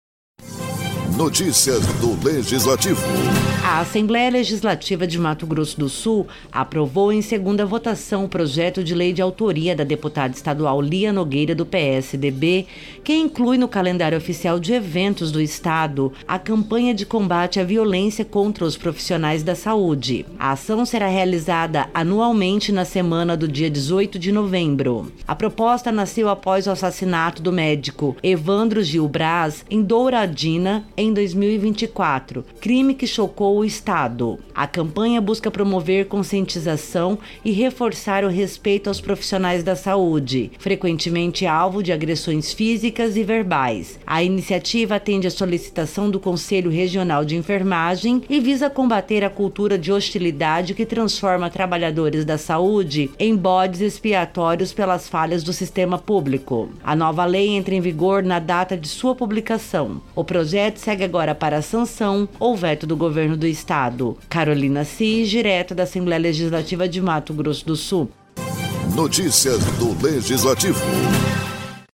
Produção e Locução: